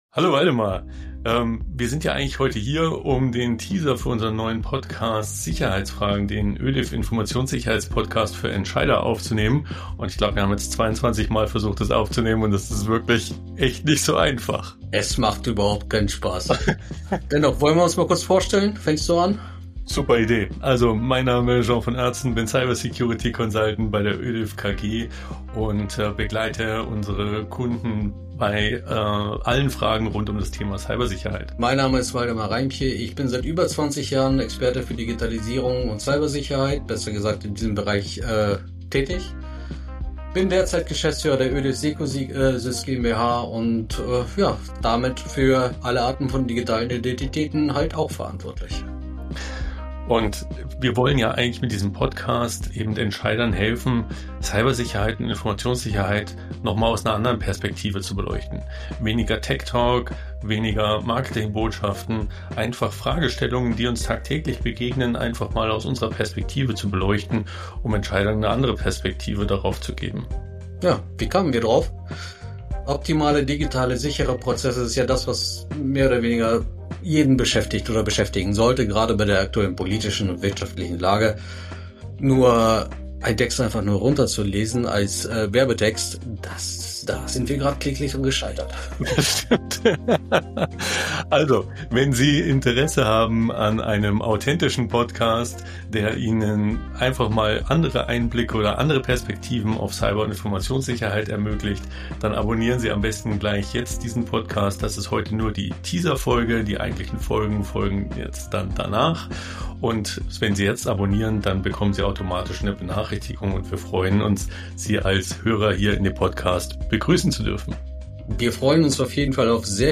In dieser etwas humorvollen Teaser-Folge stellen sich